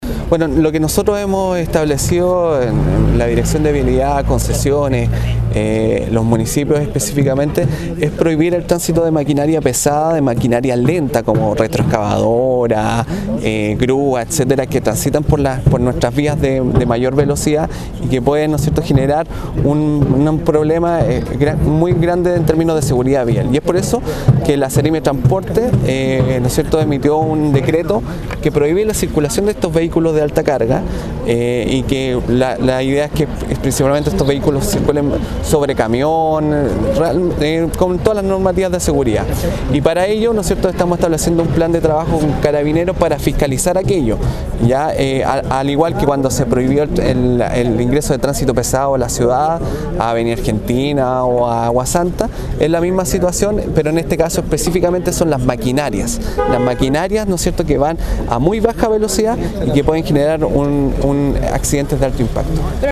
Ante esto, el seremi de Obras Publicas Miguel Saavedra, dijo que está prohibida la circulación de vehículos de alta carga, ya que su circulación a velocidad lenta puede generar accidentes en la ruta.